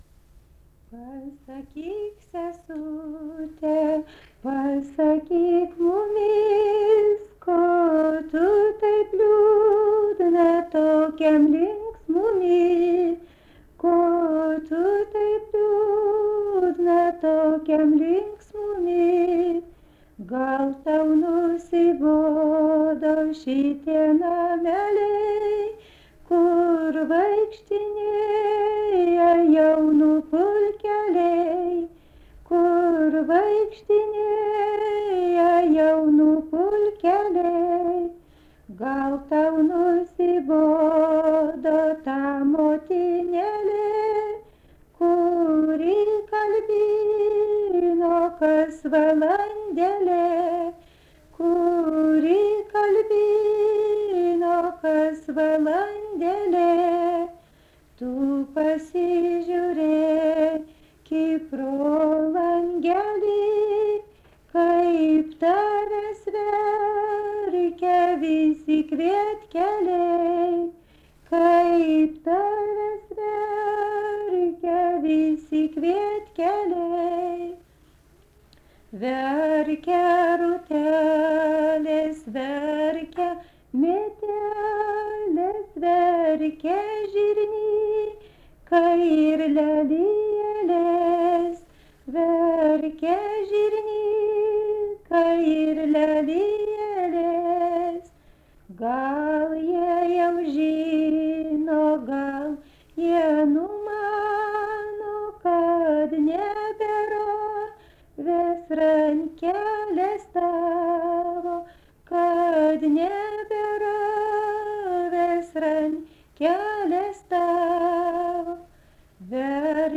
daina
Aleksandravėlė
vokalinis